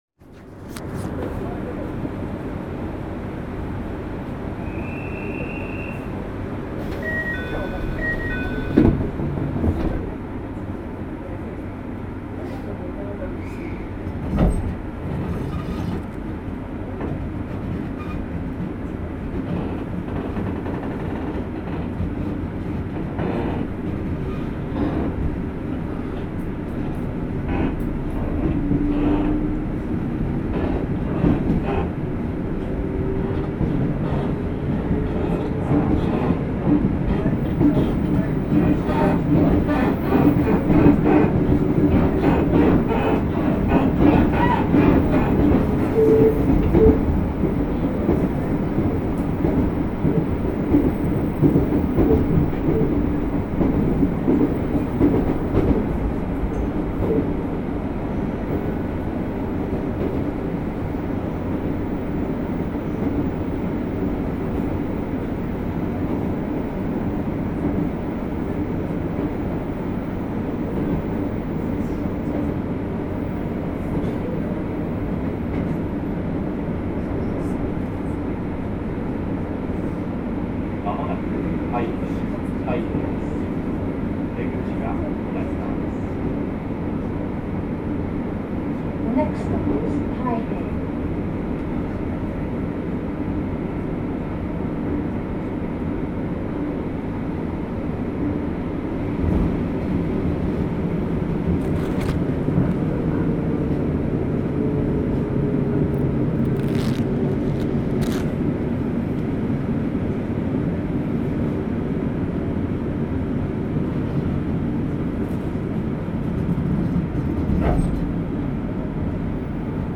走行音
録音区間：百合が原～太平(お持ち帰り)